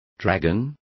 Complete with pronunciation of the translation of dragons.